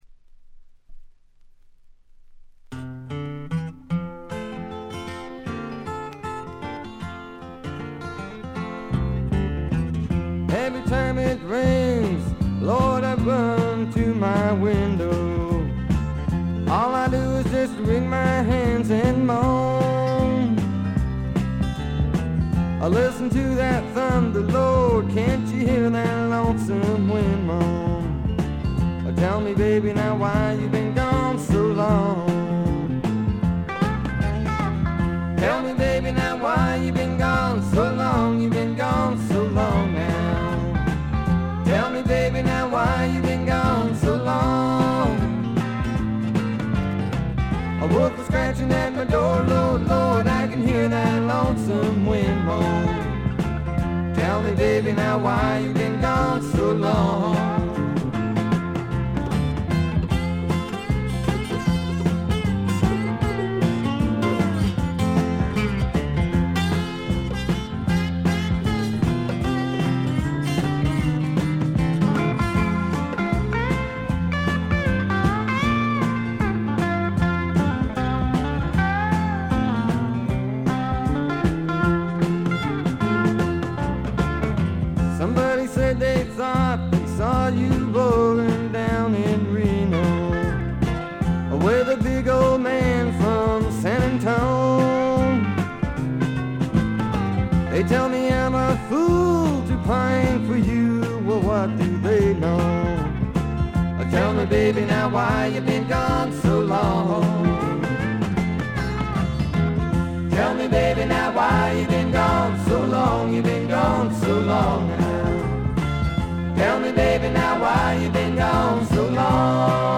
ほとんどノイズ感無し。
試聴曲は現品からの取り込み音源です。
Electric & Acoustic Guitars, Lead Vocals
Fiddle
Drums
Mandolin & Vocals
Slide Gitar on A-4